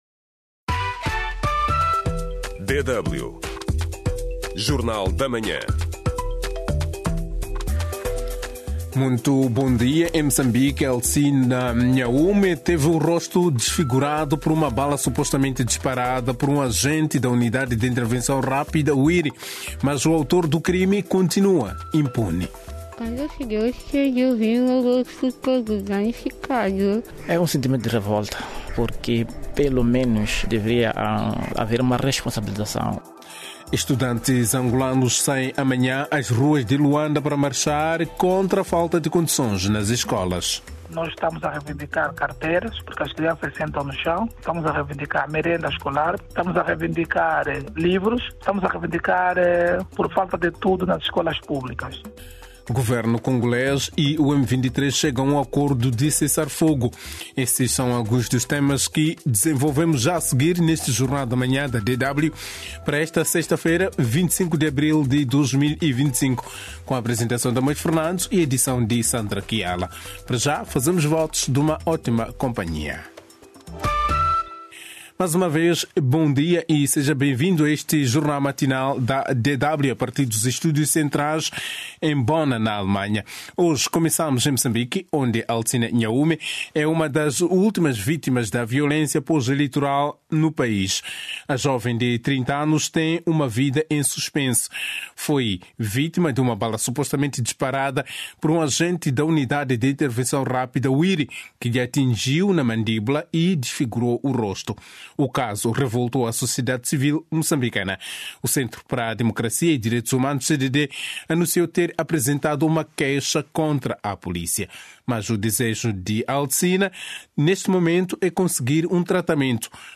… continue reading 270 tập # Notícias Internacionais # Notícias # Notícias Diárias # Portugal